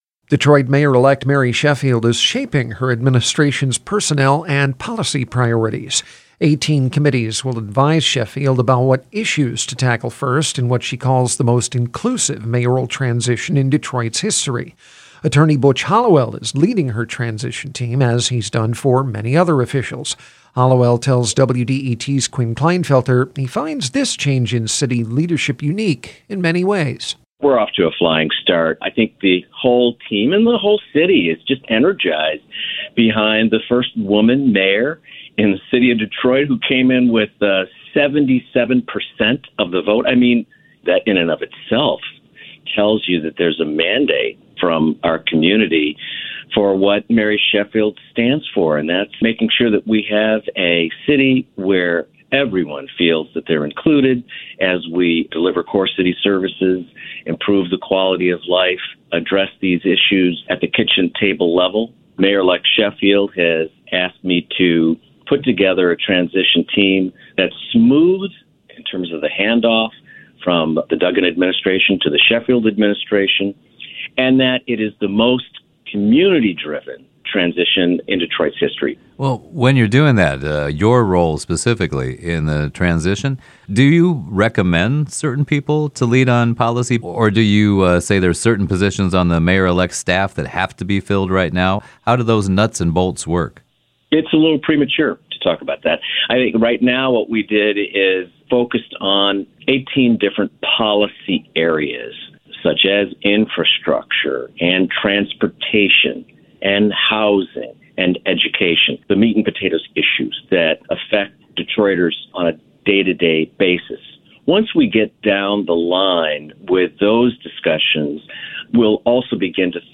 The following interview has been edited for clarity.